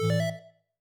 Techmino/media/effect/chiptune/connect.ogg at bc5193f95e89b9c6dfe4a18aee2daa7ea07ff93e
connect.ogg